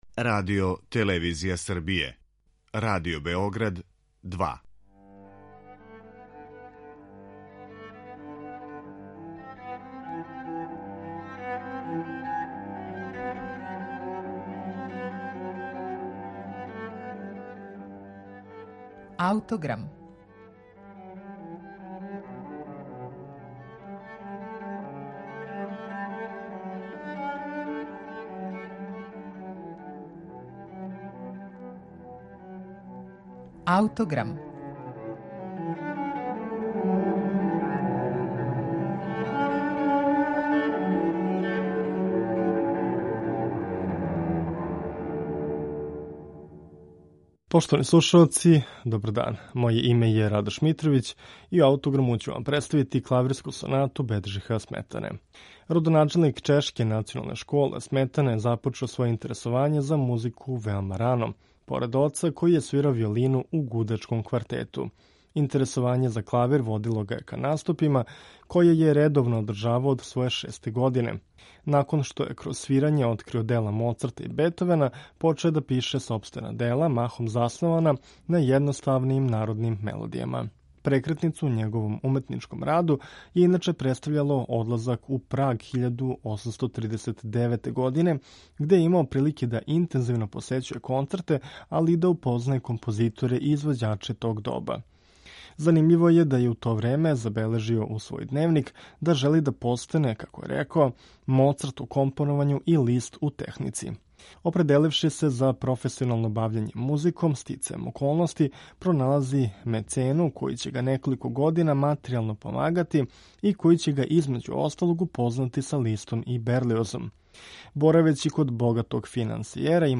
Беджих Сметана: Клавирска соната